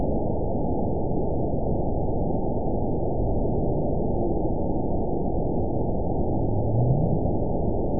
event 920608 date 03/31/24 time 23:15:35 GMT (1 year, 1 month ago) score 9.20 location TSS-AB07 detected by nrw target species NRW annotations +NRW Spectrogram: Frequency (kHz) vs. Time (s) audio not available .wav